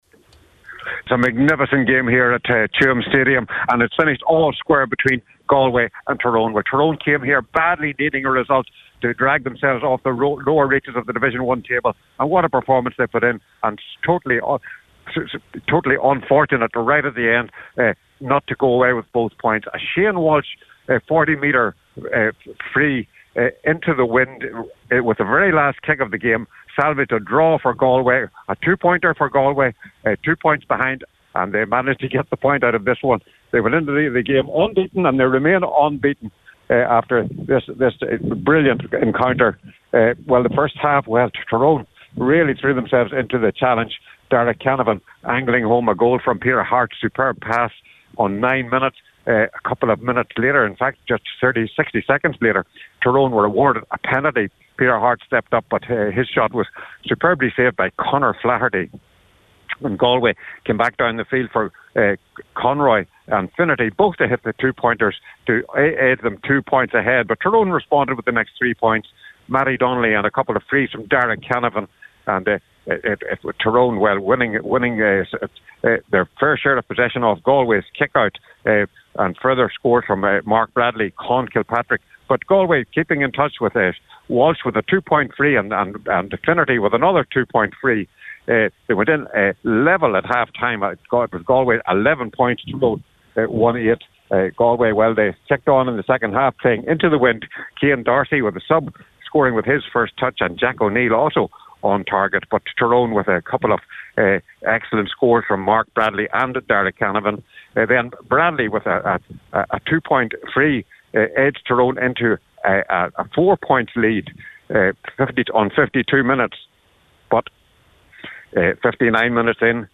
With the full time report